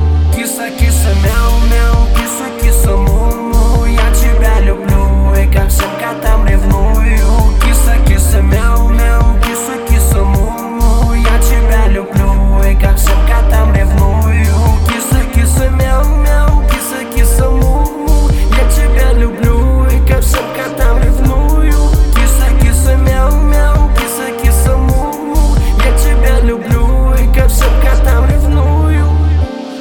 лирика
Хип-хоп
русский рэп
романтичные